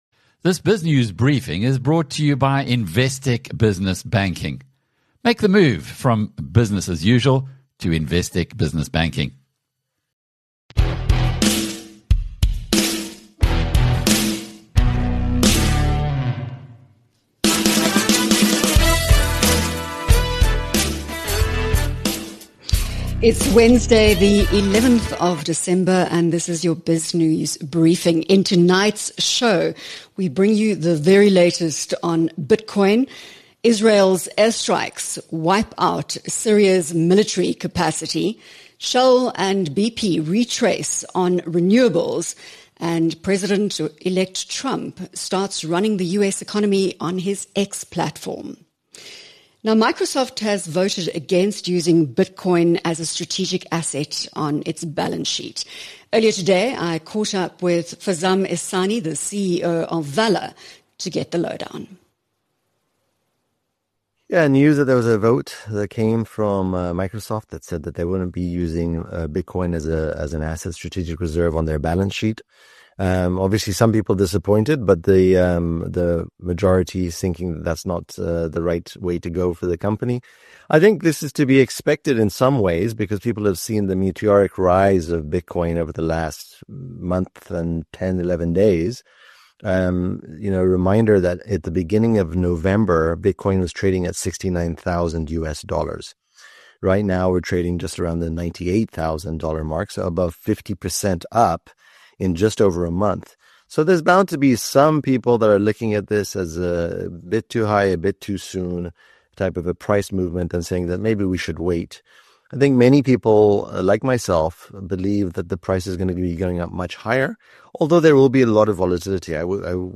In a wide-ranging conversation with BizNews, he …